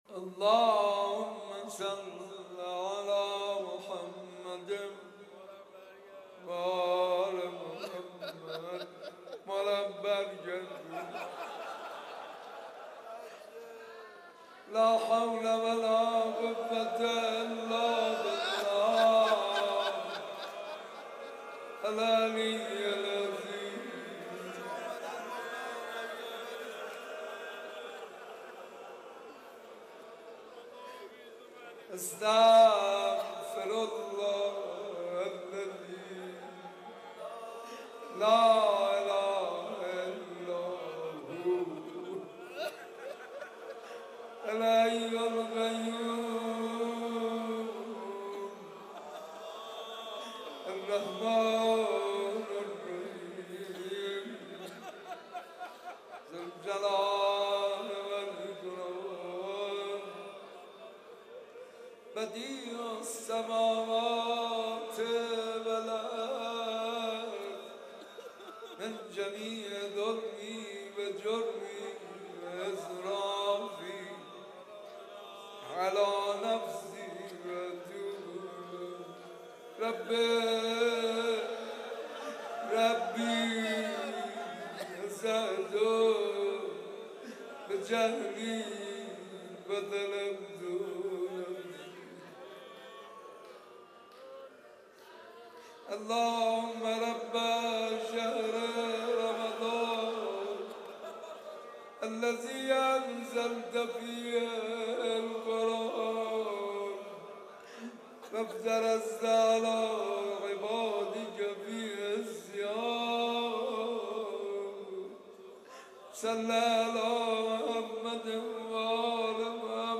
حاج منصور ارضی-شب پنجم ماه مبارک رمضان